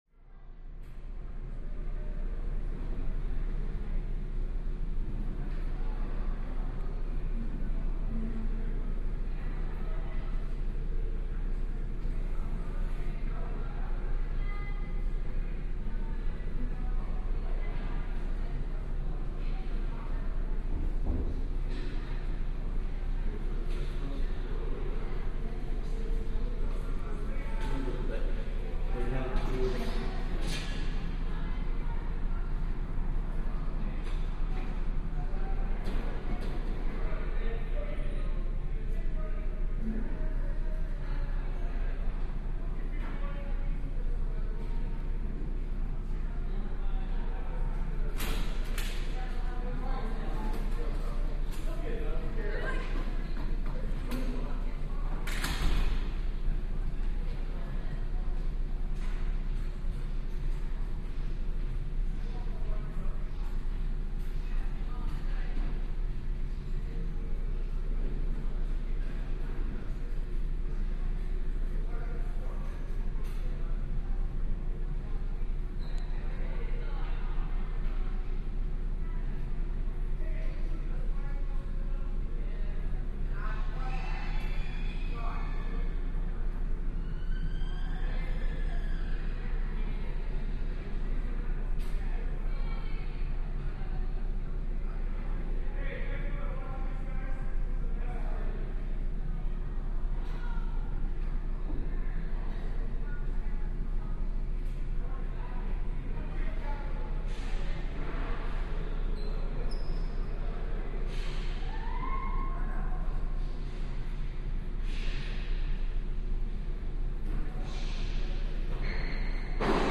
High School Hallway Ambience, Distant To Close Up Reverberant Voices